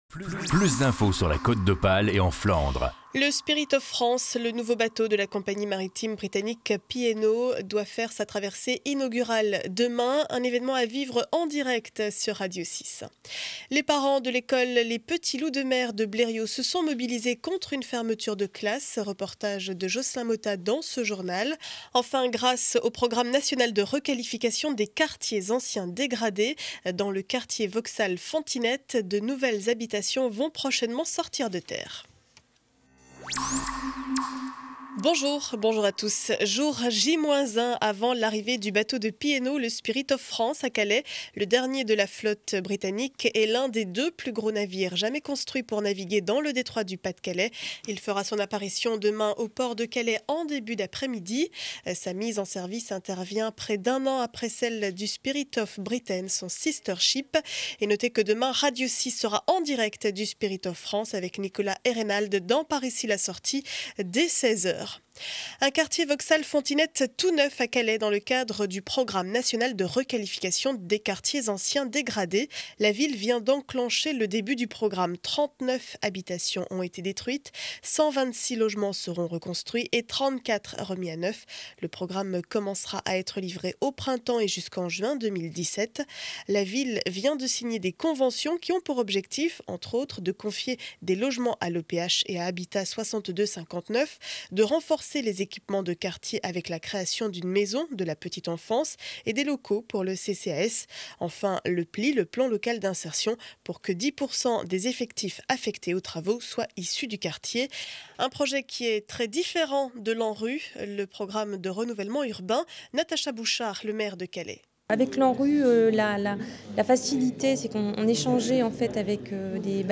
Journal du mercredi 08 février 2012 12 heures édition du Calaisis.